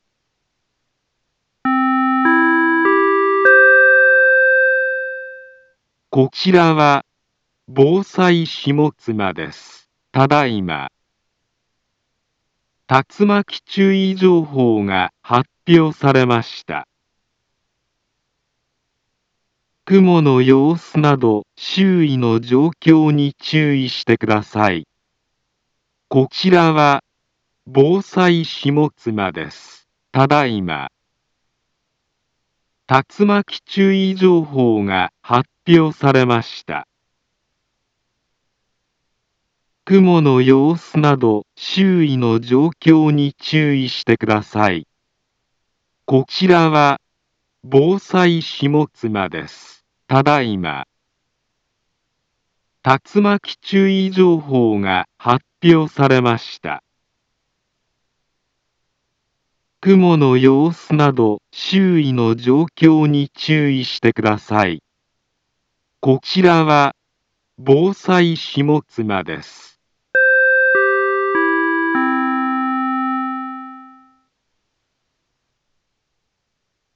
Back Home Ｊアラート情報 音声放送 再生 災害情報 カテゴリ：J-ALERT 登録日時：2021-07-11 15:25:11 インフォメーション：茨城県南部は、竜巻などの激しい突風が発生しやすい気象状況になっています。